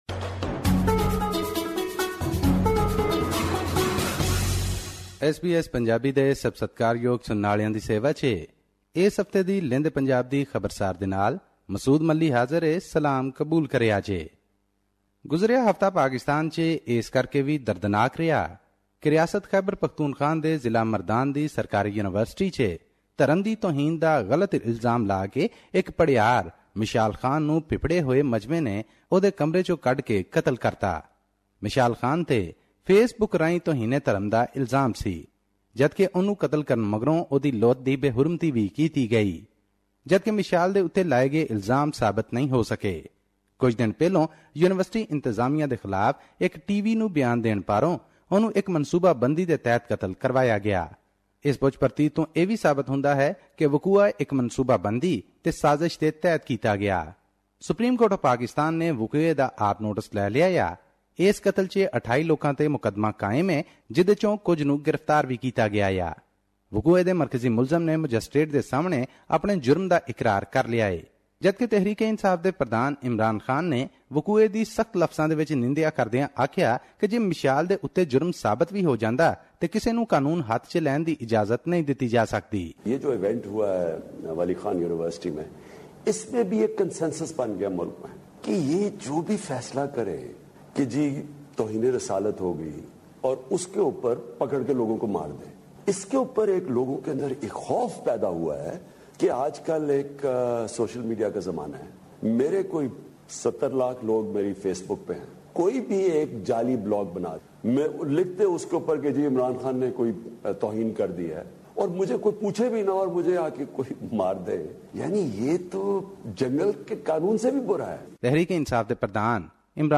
His report was presented on SBS Punjabi program on Monday, April 18, 2017, which touched upon issues of Punjabi and national significance in Pakistan.